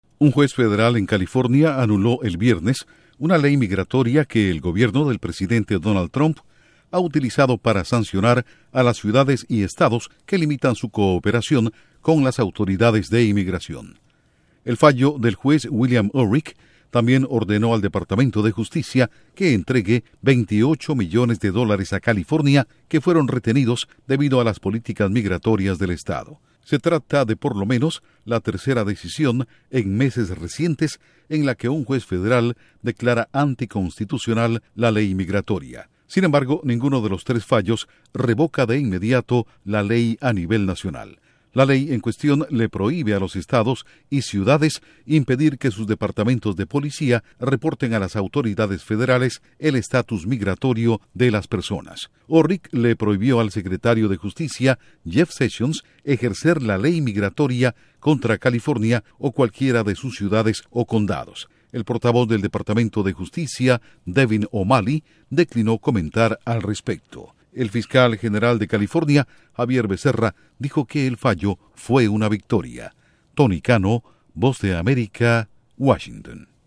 Juez federal: Gobierno de EE.UU. no puede vincular fondos a migración a ciudades que no cooperan con autoridades migratorias. Informa desde la Voz de América en Washington